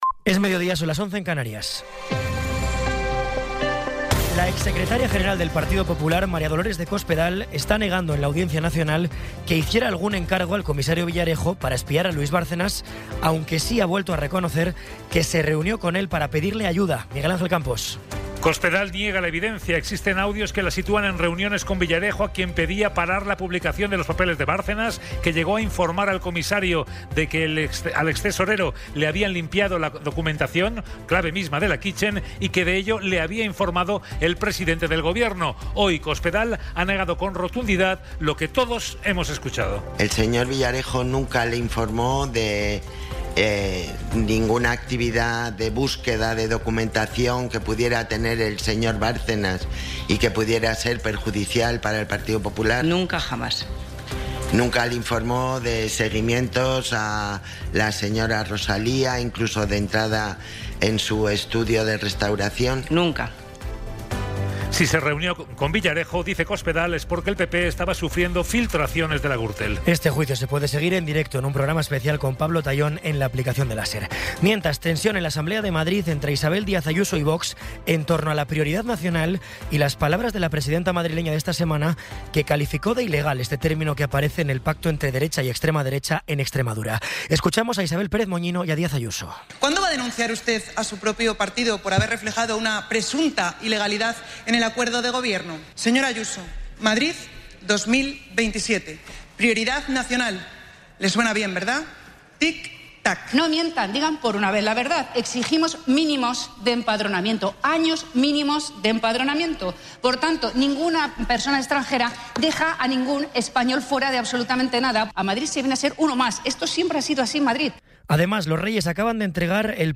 Resumen informativo con las noticias más destacadas del 23 de abril de 2026 a las doce.